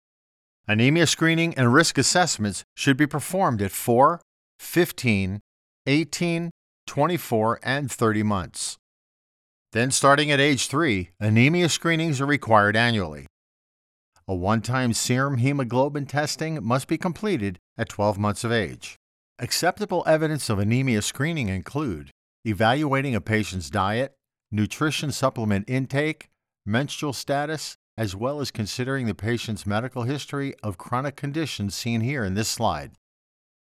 Professional Voice Talent With 5 years experience.
North Eastern US NJ, NY, Mass.
Aetna Med i Cal Narration.mp3